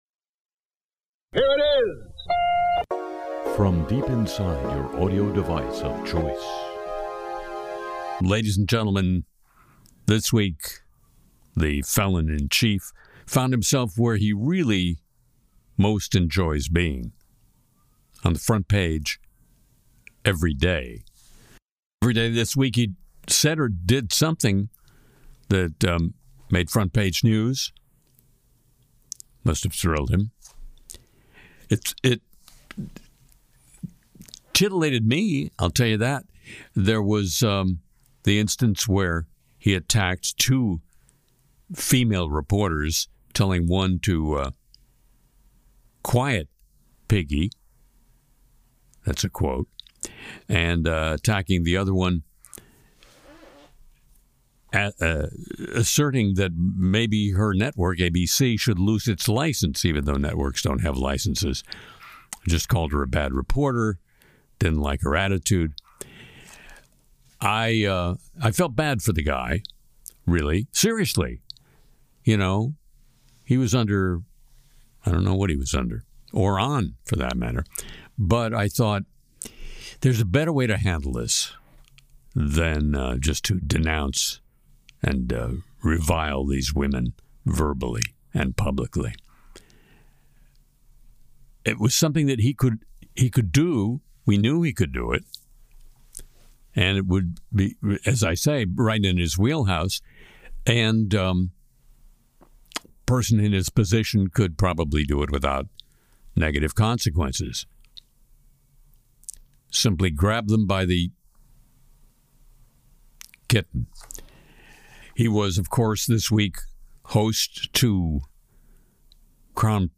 Harry sings “Things Happen,” spoofs startup culture with “The Entrepod,” digs into AI power demands, deepfake leaks, Musk’s Grok rewrites, and this week’s The Apologies of the Week.